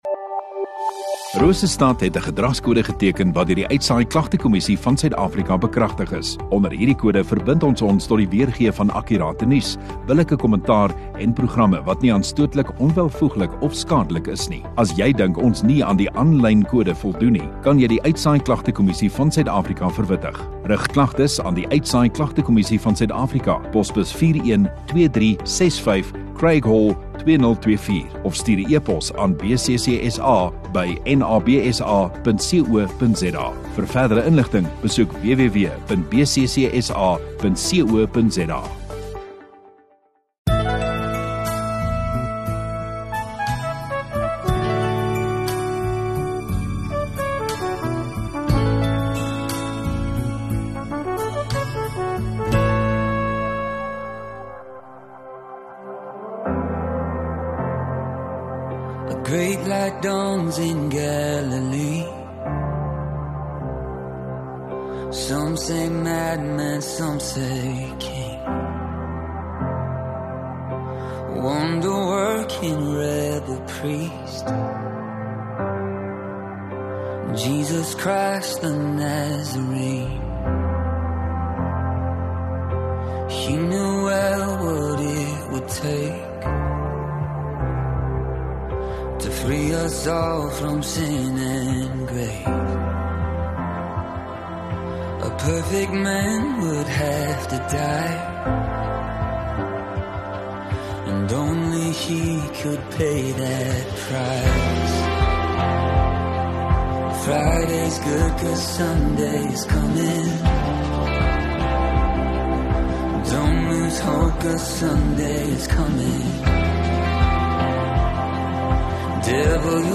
(Goeie Vrydag) Vrydagaand Erediens